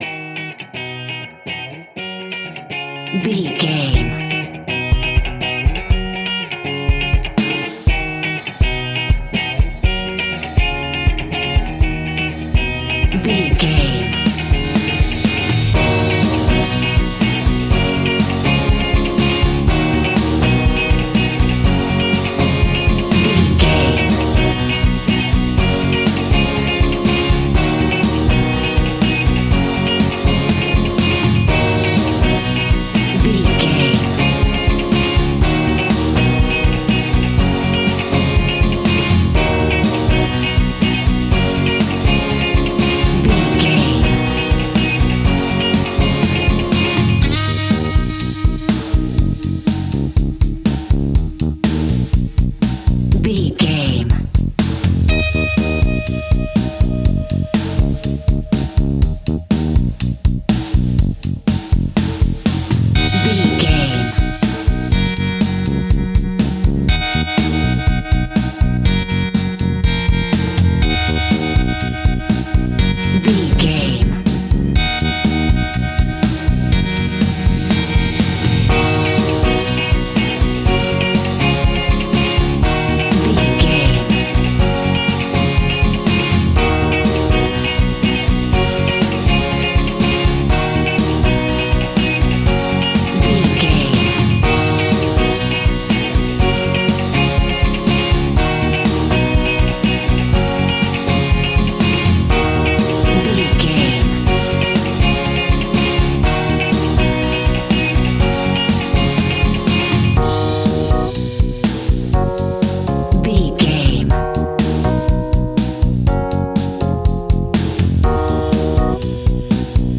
Ionian/Major
E♭
pop rock
indie pop
energetic
uplifting
electric guitar
Distorted Guitar
Rock Bass
Rock Drums
hammond organ